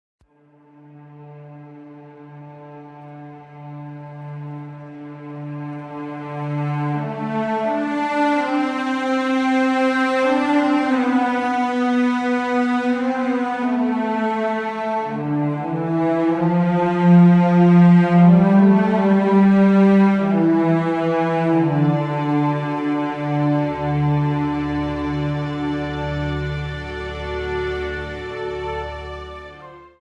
Trimmed to 30 seconds, with a fade out effect